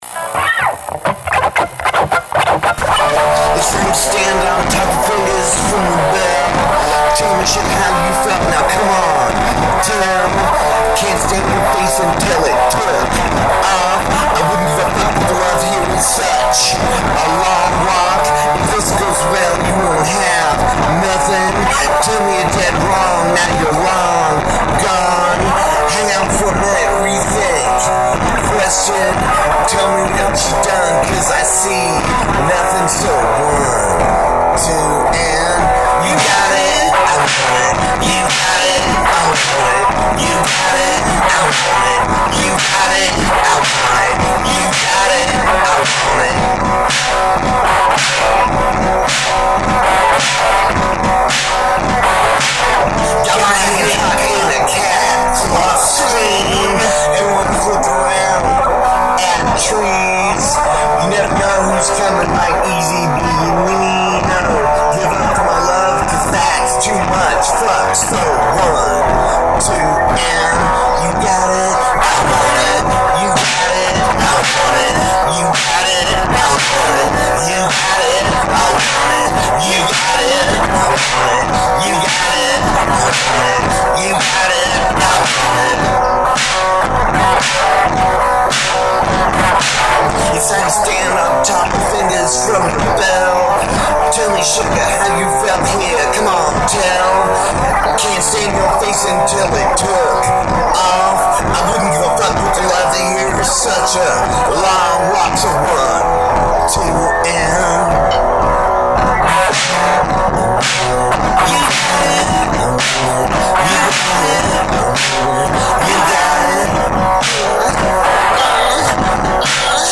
completing the song in a little over 2 minutes.